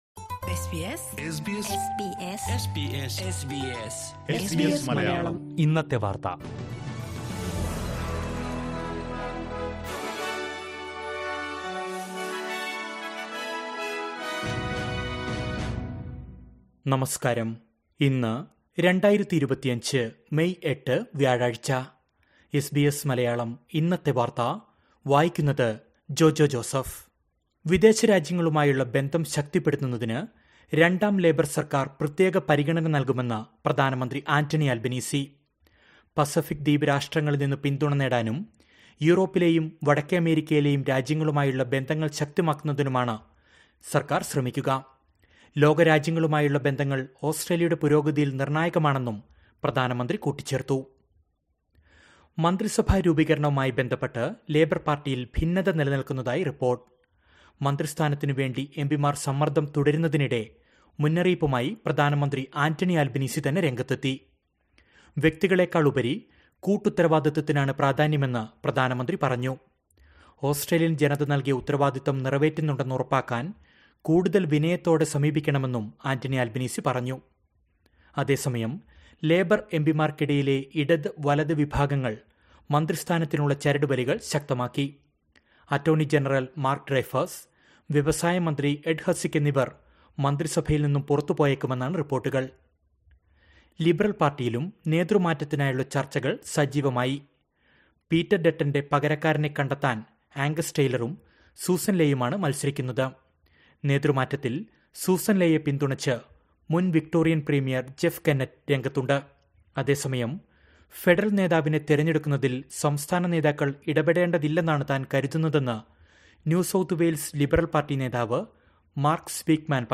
2025 മേയ് എട്ടിലെ ഓസ്ട്രേലിയയിലെ ഏറ്റവും പ്രധാന വാർത്തകൾ കേൾക്കാം...